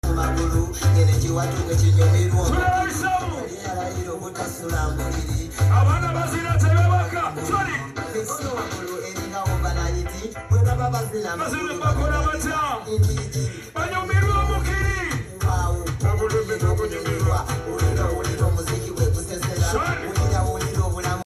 concert live now